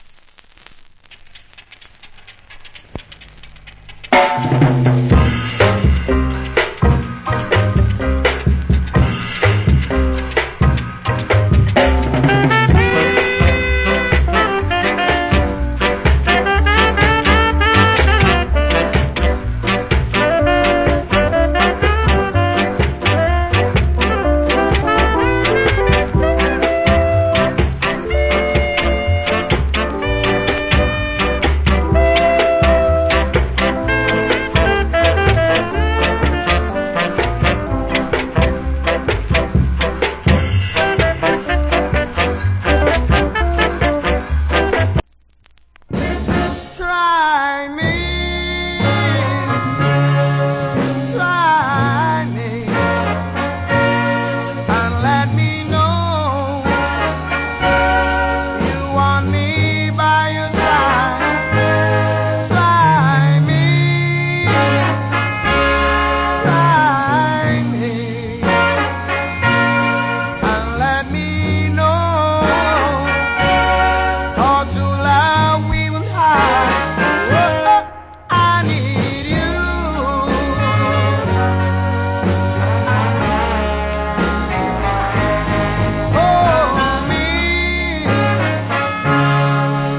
Wicked Ska Inst.